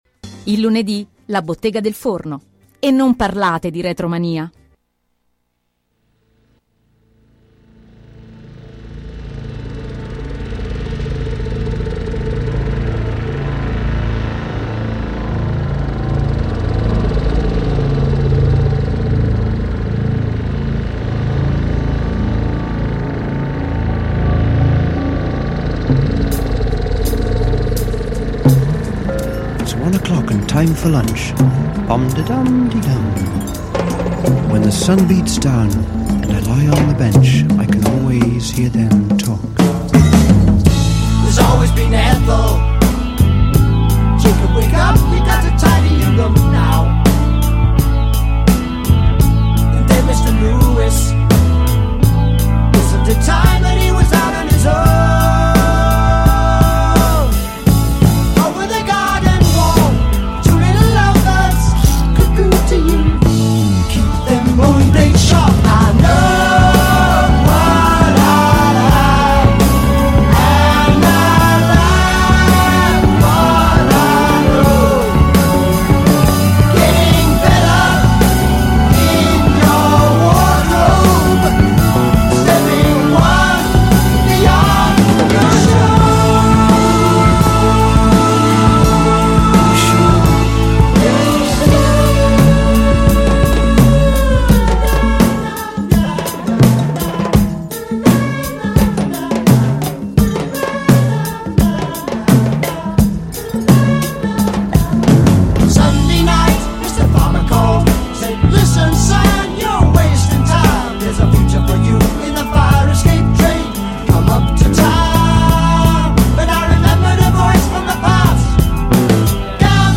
I 50 anni di Selling England By the Pound – Intervista